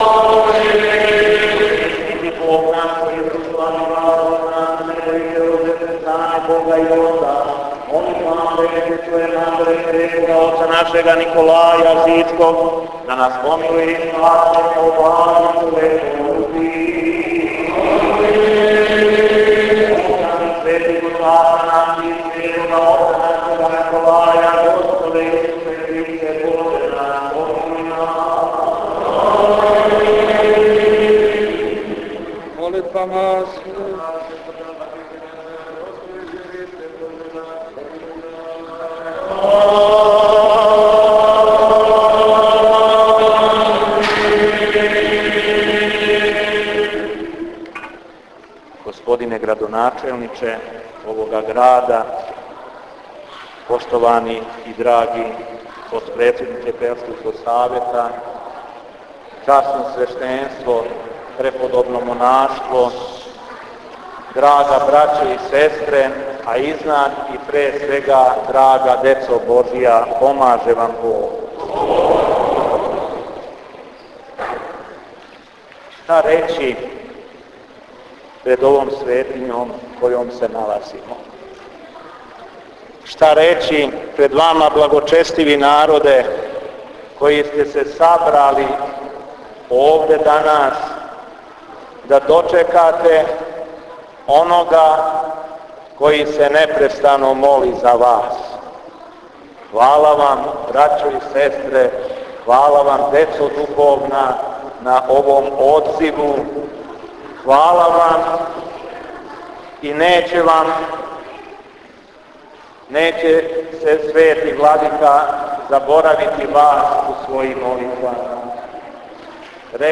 Беседа епископа шумадијског и администратора жичког Г. Јована